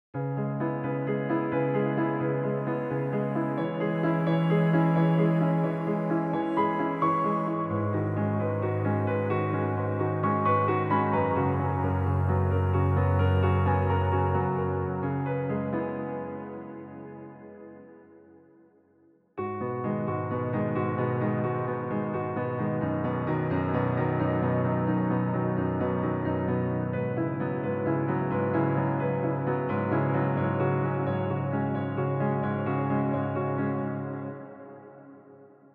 piano_full.wav